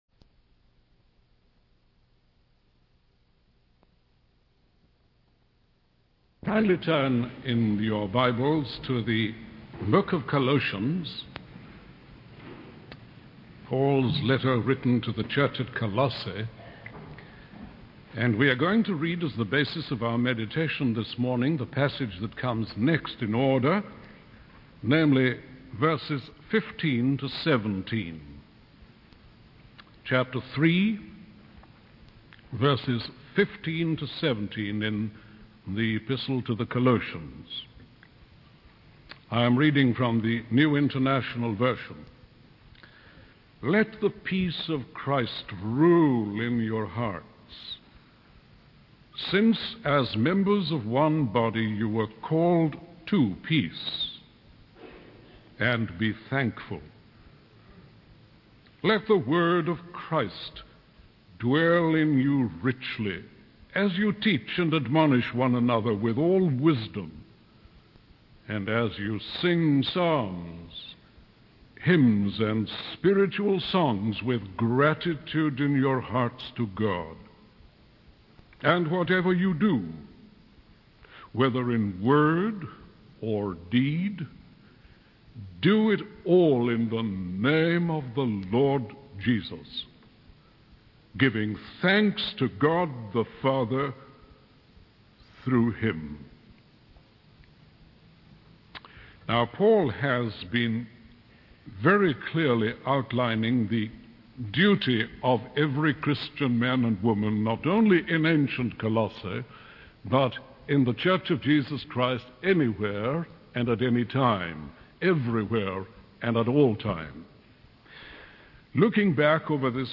In this sermon, the speaker emphasizes the duty of every Christian to be active and productive in their faith. He explains that as Christians, we should be constantly getting rid of negative behaviors and replacing them with the graces of Jesus Christ.